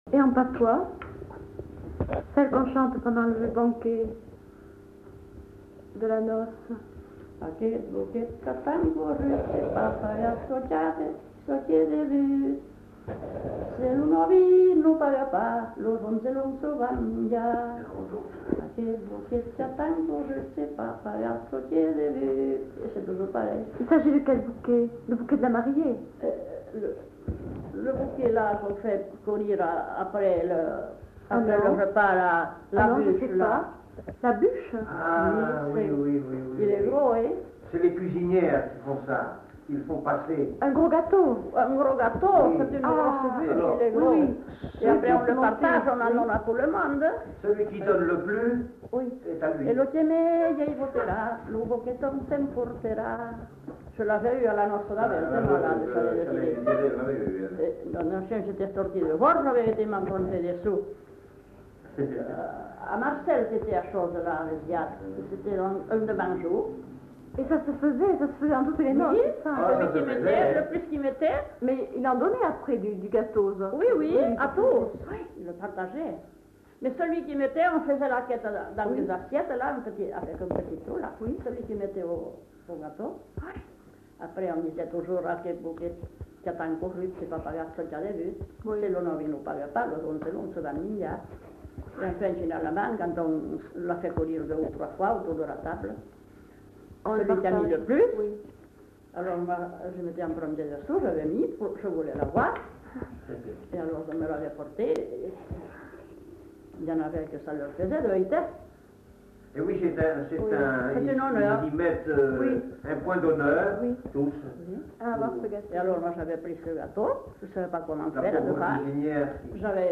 [Brocas. Groupe folklorique] (interprète)
Lieu : [sans lieu] ; Landes
Genre : chant
Effectif : 1
Type de voix : voix de femme
Production du son : chanté